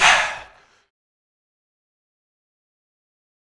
MurdaChant.wav